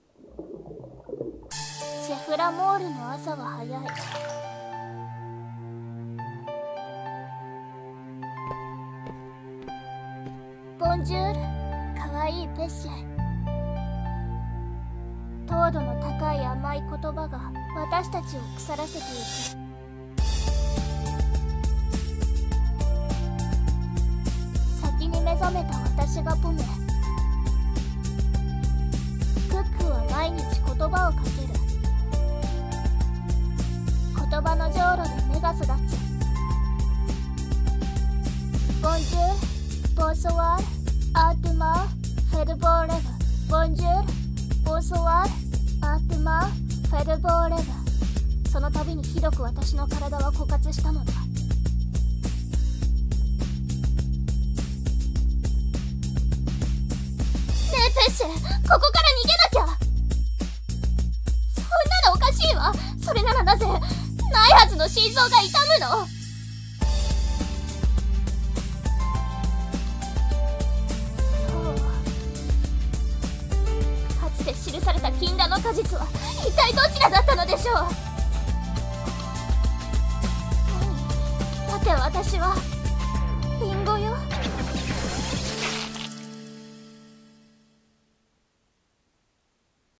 CM風声劇「ポミェとペッシェ」